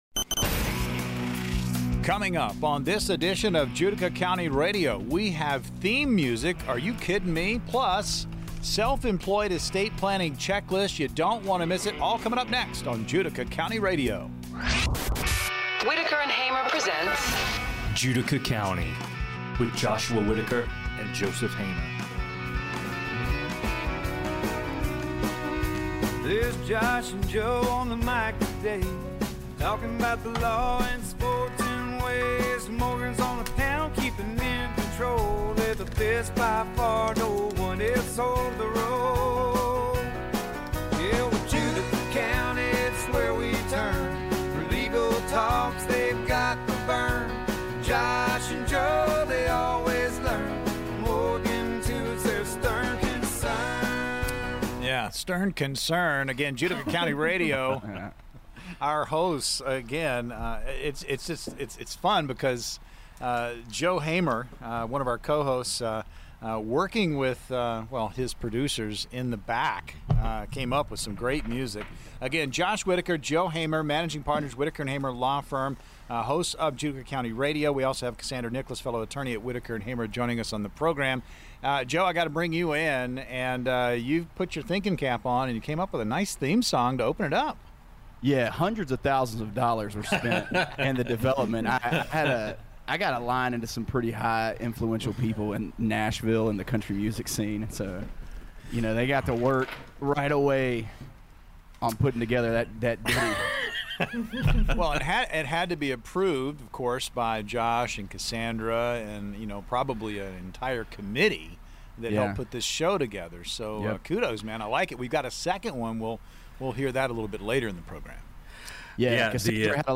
Our attorney's have some fun with Artificial Intelligence(AI) in the form of show intros. Q&A this week is a best of focusing on estate planning.